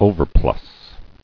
[o·ver·plus]